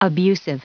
Prononciation audio / Fichier audio de ABUSIVE en anglais
Prononciation du mot abusive en anglais (fichier audio)